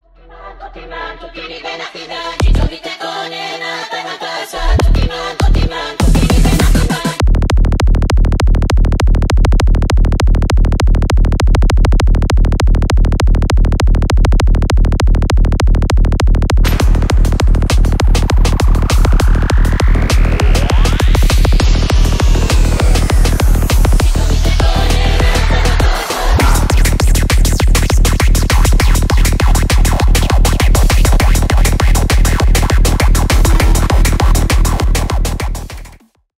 Ремикс # Электроника
ритмичные